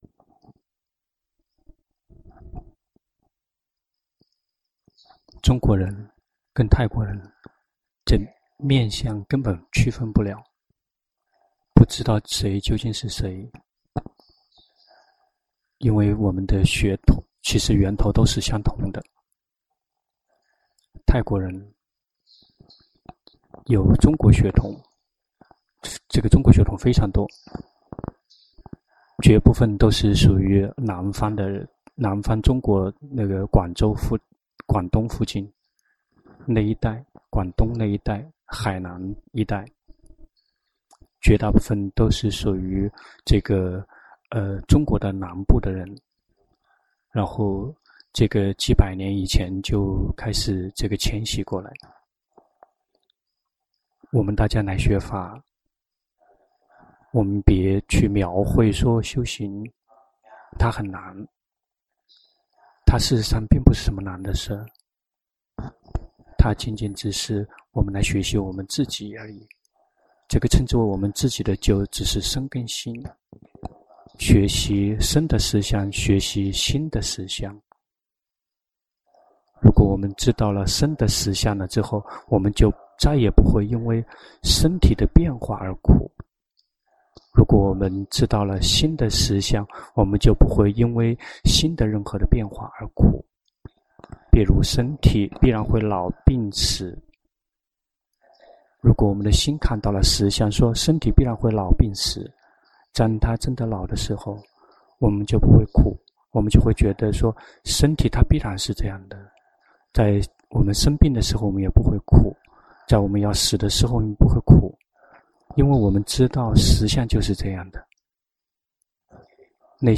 長篇法談｜為了離苦而必須做的工作——隆波帕默尊者 - 靜慮林
泰國解脫園寺 同聲翻譯